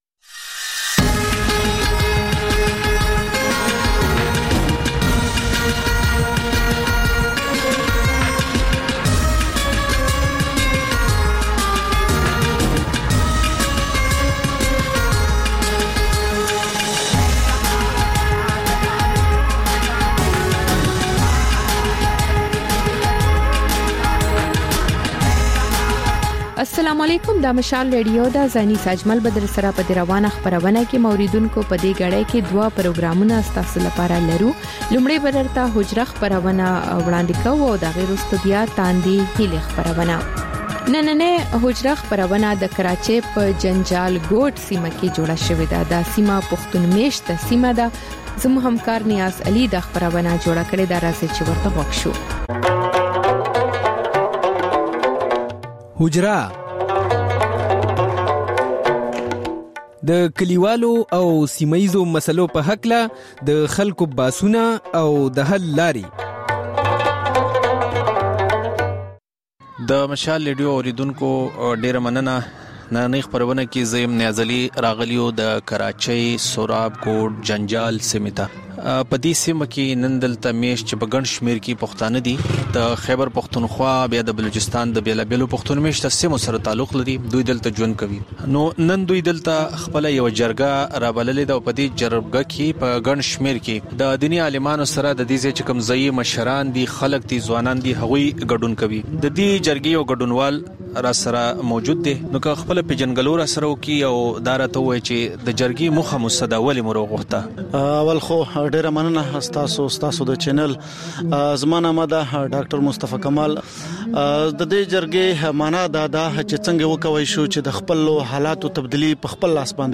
په دې خپرونه کې تر خبرونو وروسته بېلا بېل رپورټونه، شننې، مرکې خپرېږي.